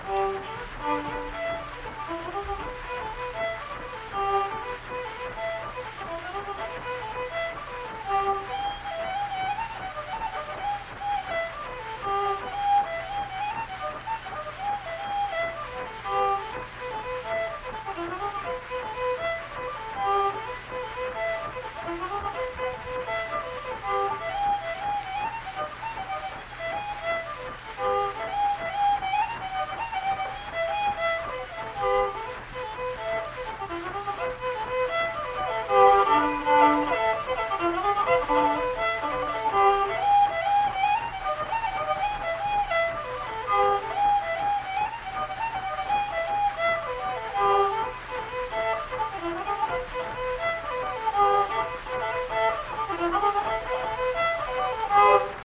Unaccompanied fiddle music
Instrumental Music
Fiddle
Harrison County (W. Va.)